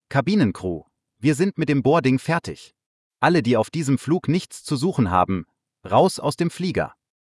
MSFS_Simbrief/Announcements/TUI/BoardingComplete.ogg at ccd615bb0707f976fde88dba4460ed0a795f70e4